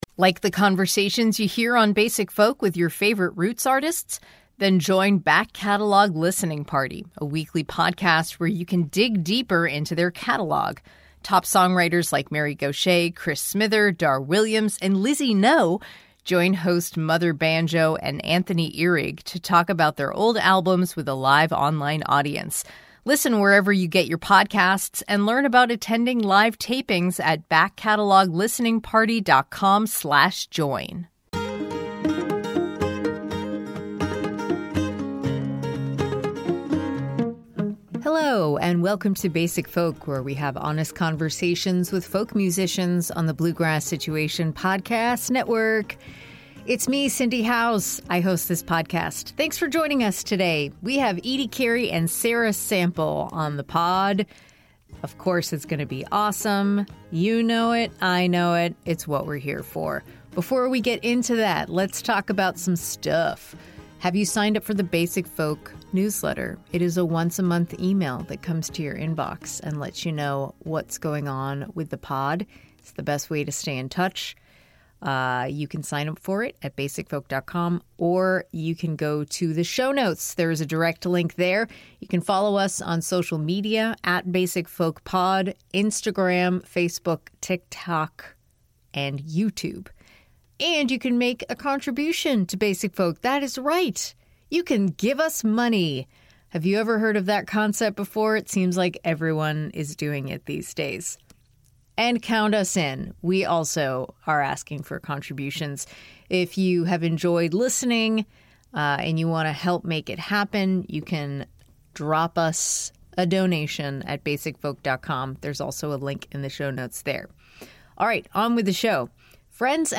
We wrap it all up, of course, by hearing each of their James Taylor stories and a delightful bedtime-themed lightning round.